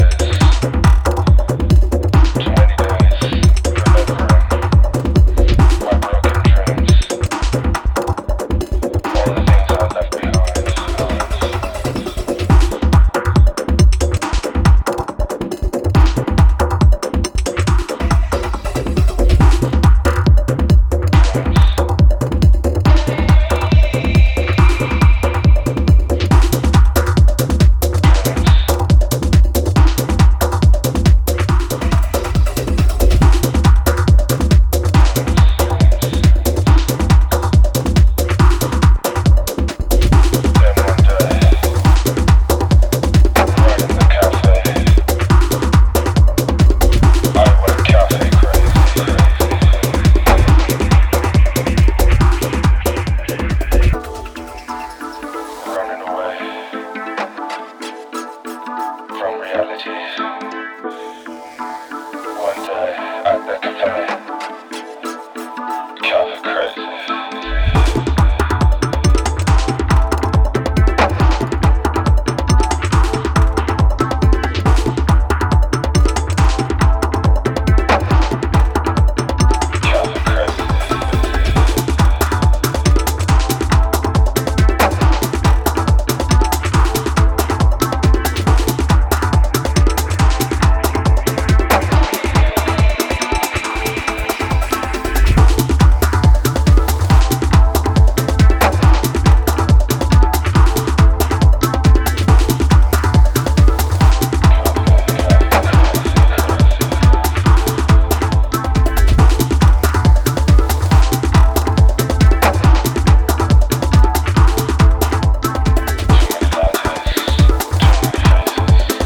a dance-pop hybrid soaked in good vibes and electronic fun.